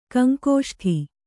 ♪ kaŋkōṣṭhi